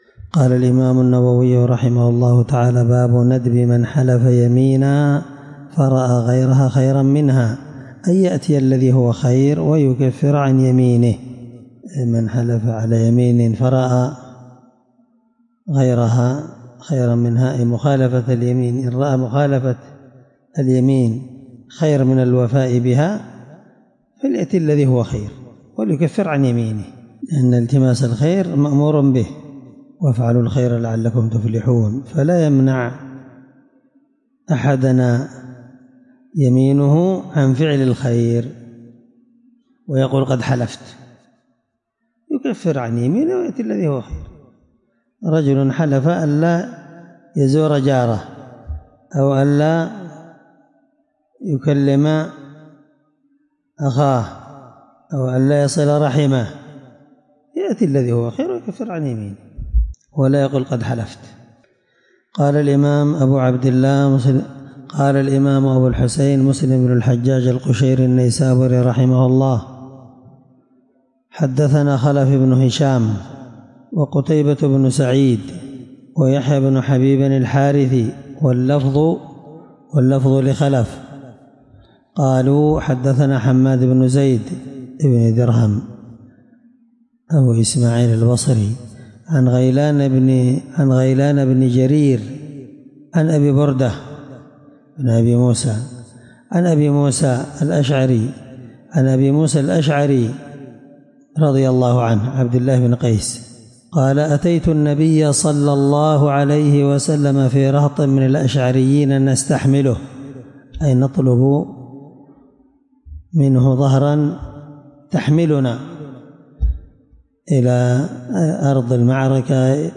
الدرس3من شرح كتاب الأيمان حديث رقم(1649) من صحيح مسلم